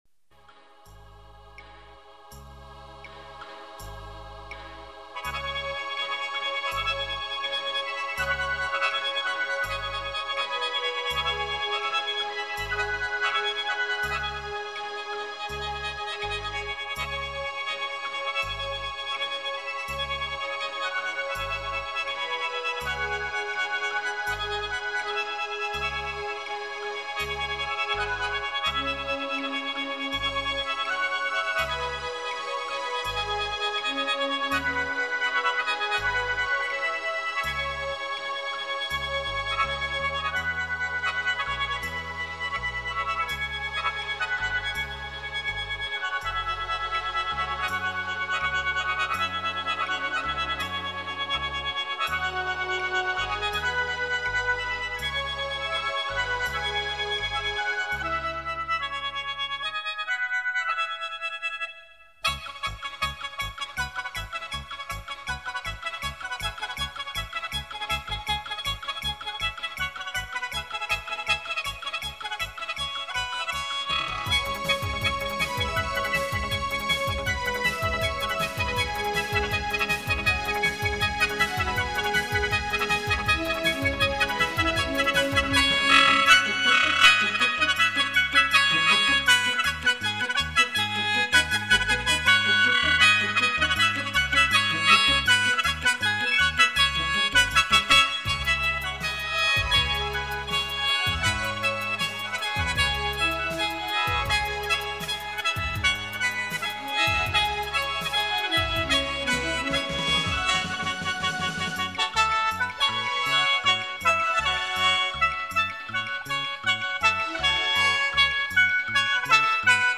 0005-笙名曲草原春牧.mp3